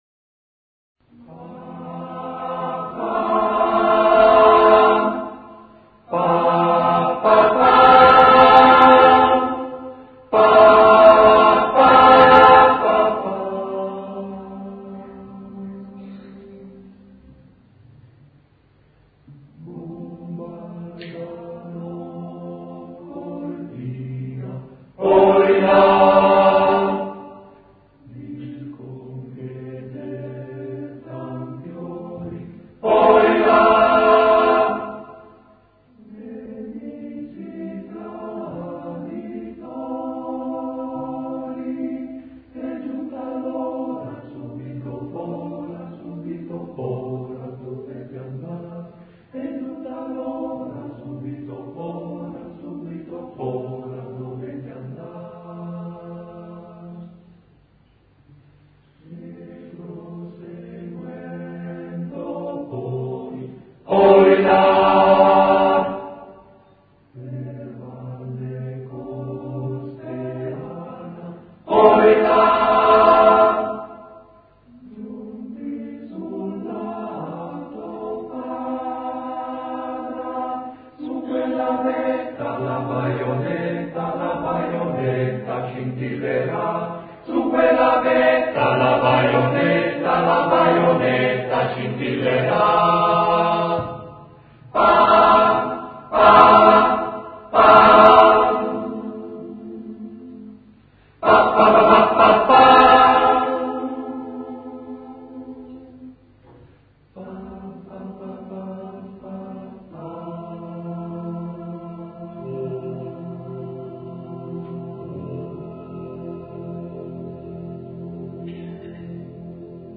Bombardano Cortina [ voci virili ] Bombardano Cortina dicon che gettan fiori nemici traditori è giunta l’ora subito fora subito fora dovete andar!
Scadica il file MP3 (Canto degli Alpini) Armonizzazione L. Pigarelli